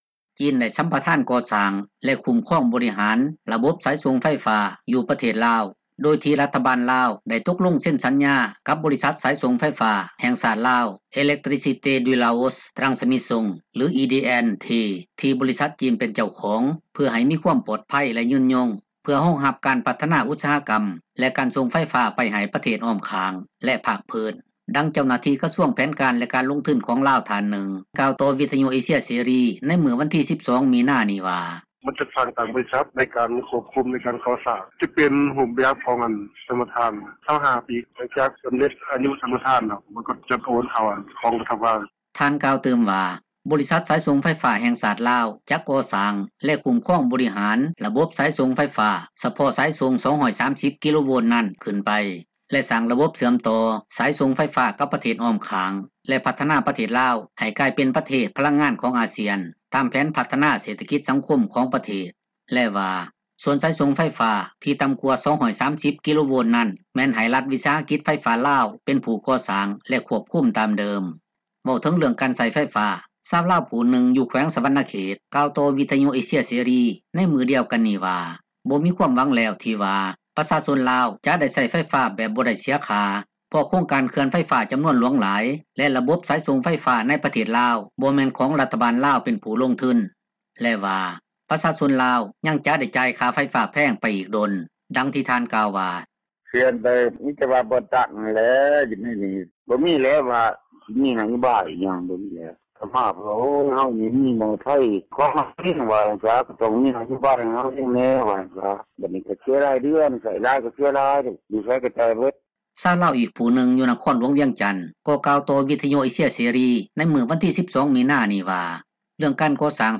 ເວົ້າເຖິງເຣື່ອງການໃຊ້ໄຟຟ້າ ຊາວລາວຜູ້ນຶ່ງຢູ່ແຂວງສວັນນະເຂດ ກ່າວຕໍ່ວິທຍຸເອເຊັຽເສຣີ ໃນມື້ດຽວກັນນີ້ວ່າບໍ່ມີຄວາມຫວັງແລ້ວ ທີ່ວ່າ ປະຊາຊົນລາວ ຈະໄດ້ໃຊ້ໄຟຟ້າແບບບໍ່ໄດ້ ເສັຍຄ່າ ເພາະໂຄງການເຂື່ອນໄຟຟ້າ ຈໍານວນຫຼວງຫຼາຍ ແລະ ຣະບົບສາຍສົ່ງ ໄຟຟ້າ ໃນປະເທດລາວ ບໍ່ແມ່ນຂອງຣັຖບາລລາວ ເປັນຜູ້ລົງທຶນ ແລະວ່າ ປະຊາຊົນລາວ ຍັງຈະໄດ້ ຈ່າຍຄ່າໄຟຟ້າແພງໄປອີກດົນ ດັ່ງທີ່ທ່ານກ່າວວ່າ: